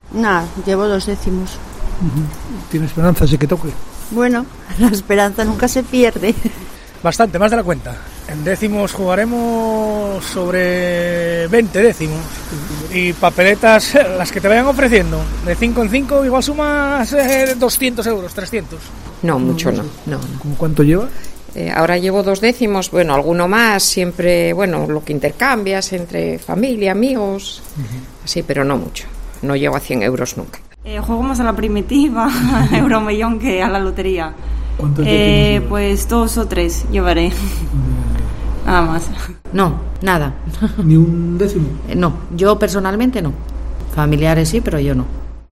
A las puertas de una administración de Lotería, nos encontramos a varias señoras que aceptan amablemente atender las preguntas de COPE y nos hablan de sus intenciones para el Sorteo Extraordinario de Navidad.